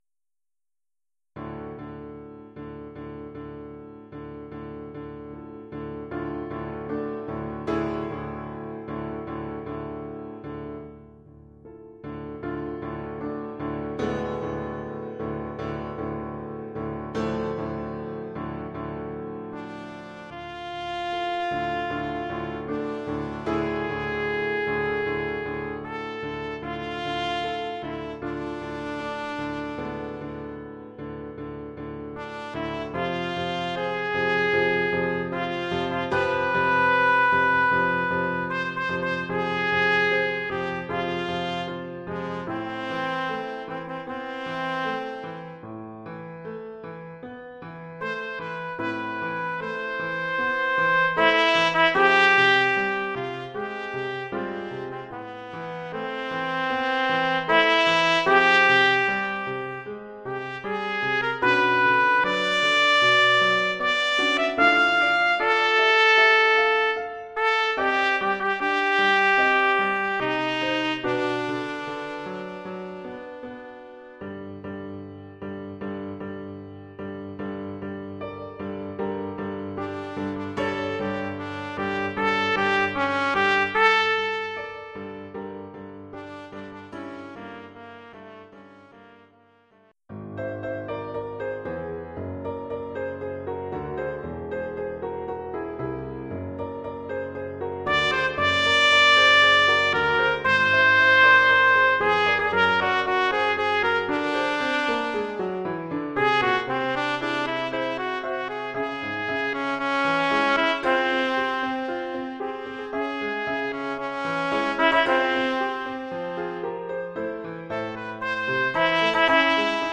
Oeuvre pour trompette ou cornet
ou bugle et piano..